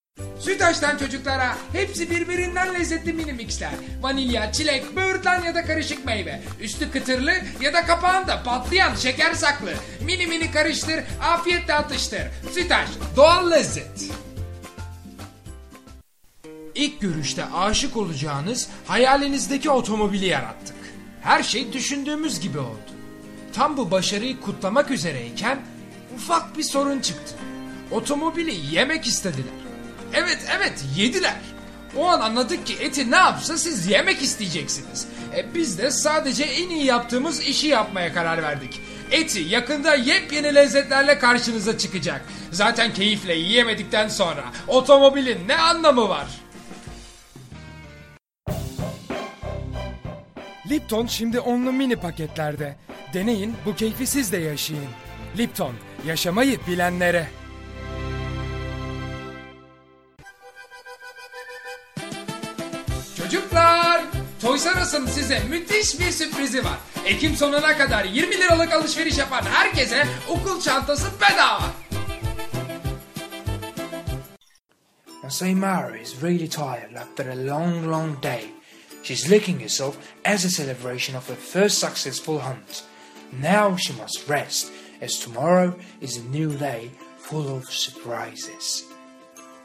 Turkish Native Speaker, young voice, energetic voice, child voice, children commercial, animation, mobile games, character voice, cartoons,
Sprechprobe: Werbung (Muttersprache):